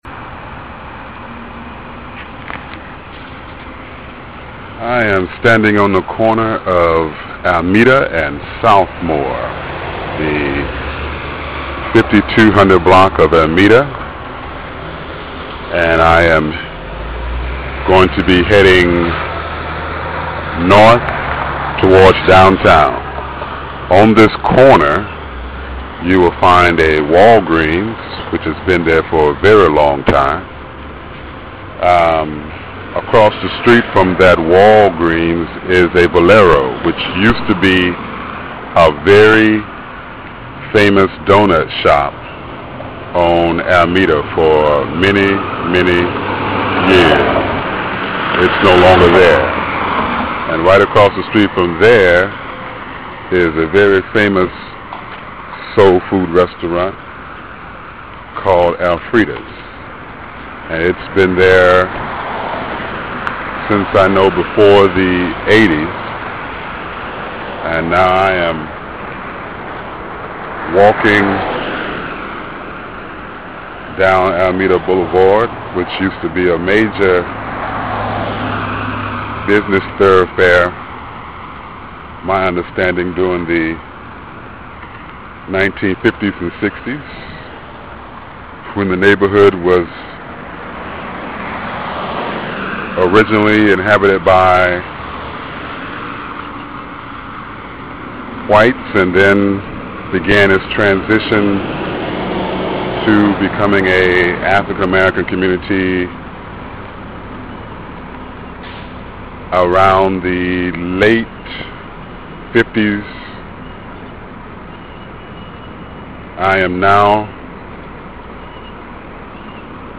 walks along Almeda from Southmore to Wheeler, in Houston’s historic Third Ward that has served as a major hub for some of Houston’s most talented artists and musicians